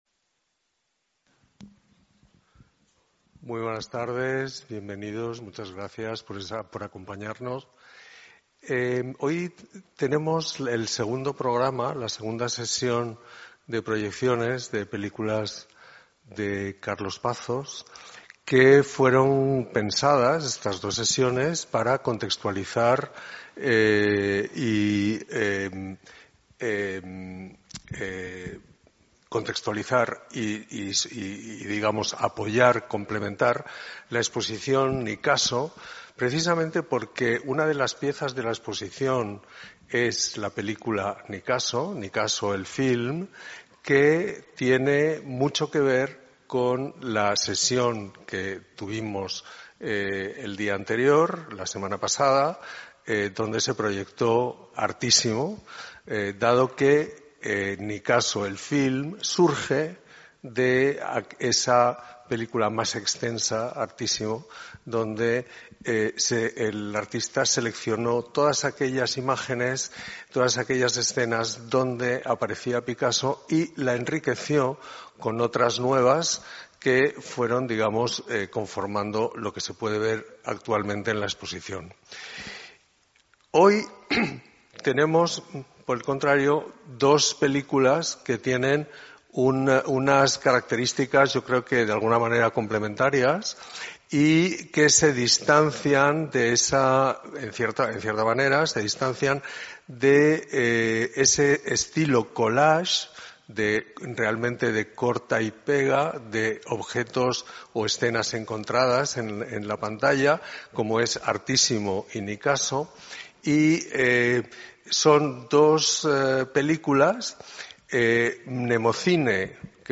SESIONES DE CINE COMENTADAS
27 Enero 2026 18:30 h Auditorio del CGAC Coordinación